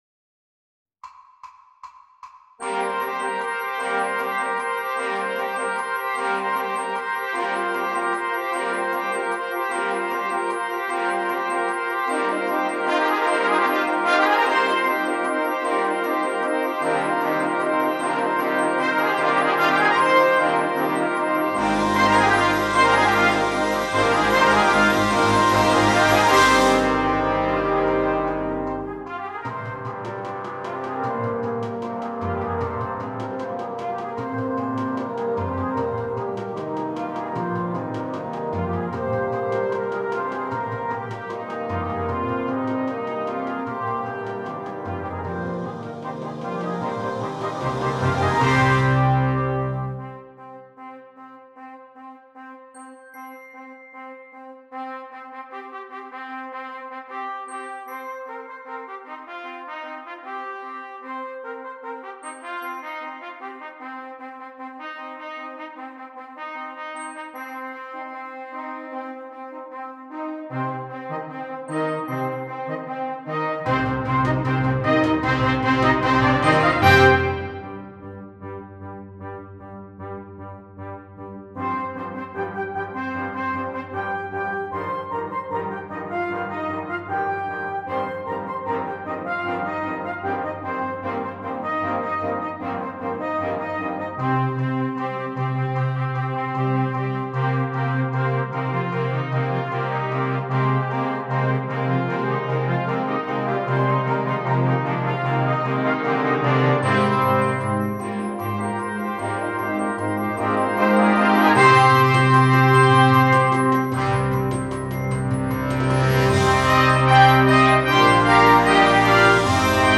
Brass Band
Canadian folk song
an excellent example of minimalist music